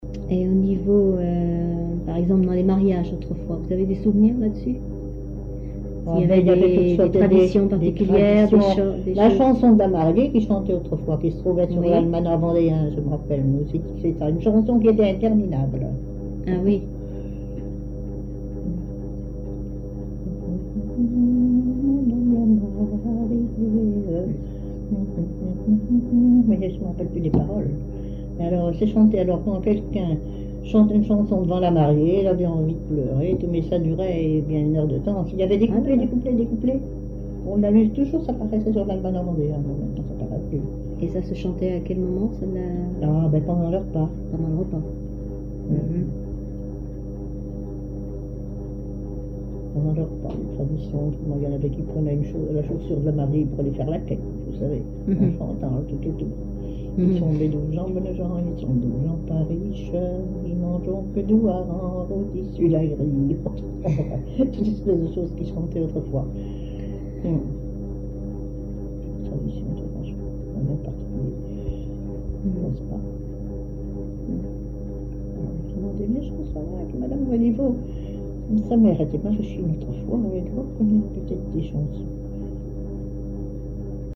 témoignages sur la vie familiale, sur le chant
Catégorie Témoignage